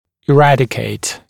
[ɪ’rædɪkeɪt][и’рэдикейт]искоренять, избавляться